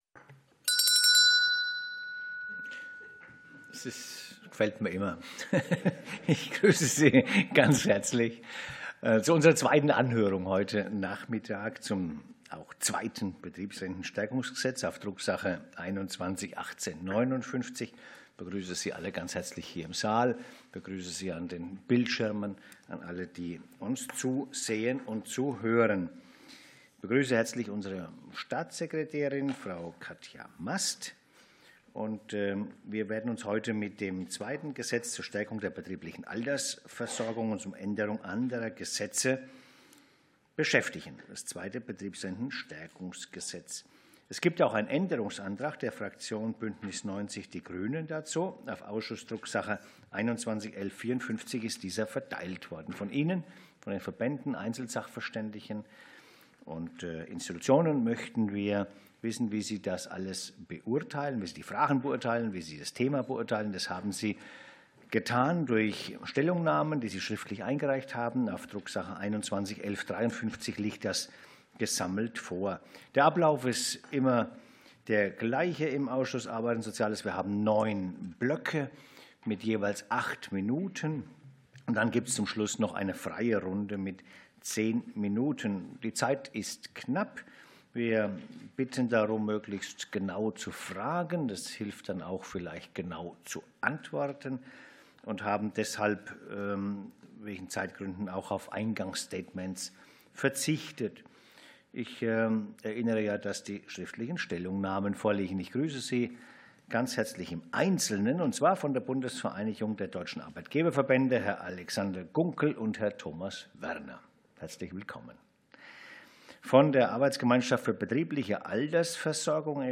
Anhörung des Ausschusses für Arbeit und Soziales